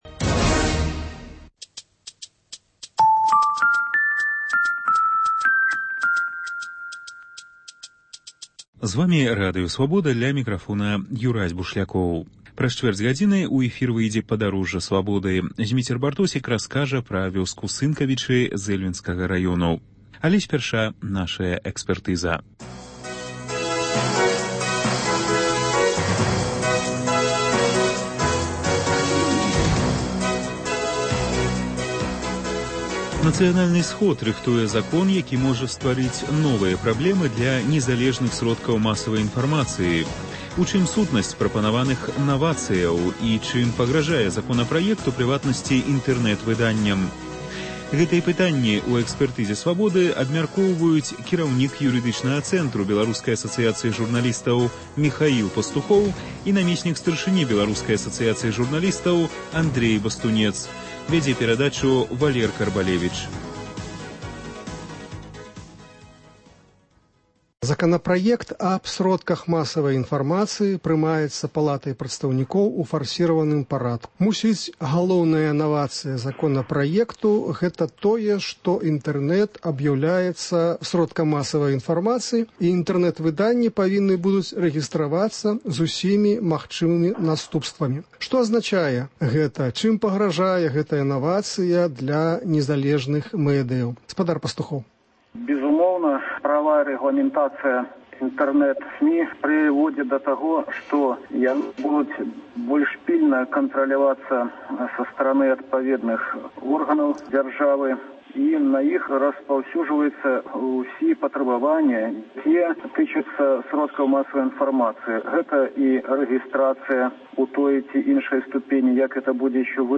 Госьць у студыі адказвае на лісты, званкі, СМСпаведамленьні